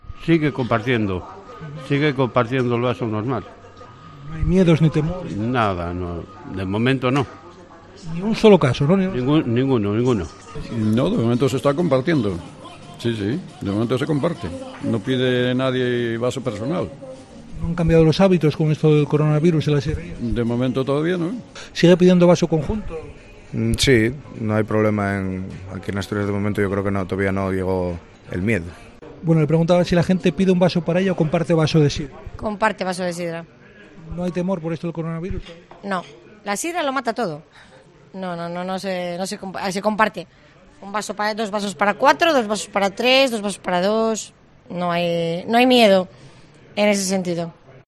Los chigreros hablan del coronavirus: los asturianos siguen compartiendo el vaso